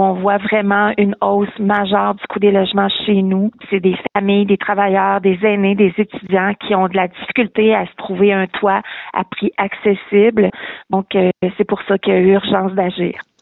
En conférence de presse mardi, la mairesse, Lucie Allard, a expliqué l’importance de ce projet.